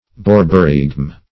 Search Result for " borborygm" : The Collaborative International Dictionary of English v.0.48: Borborygm \Bor"bo*rygm\, n. [F. borborygme, fr. Gr.